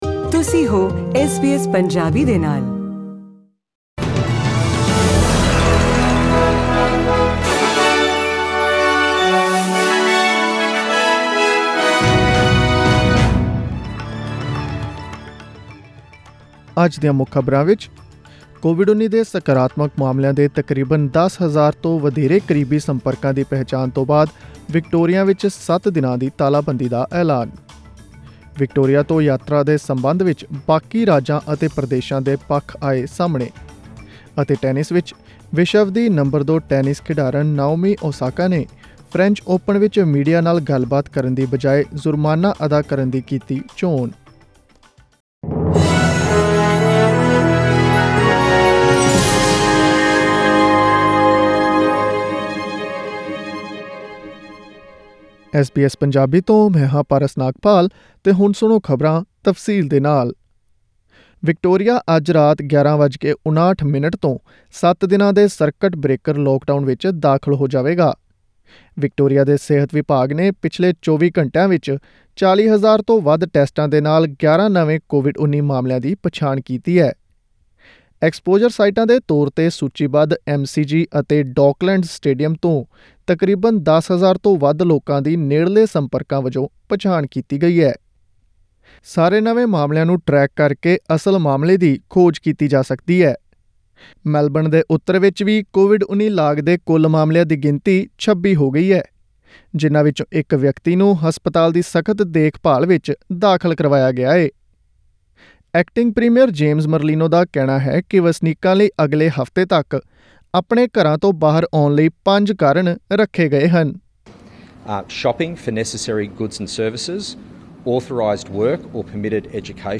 Click on the audio icon in the picture above to listen to the news bulletin in Punjabi.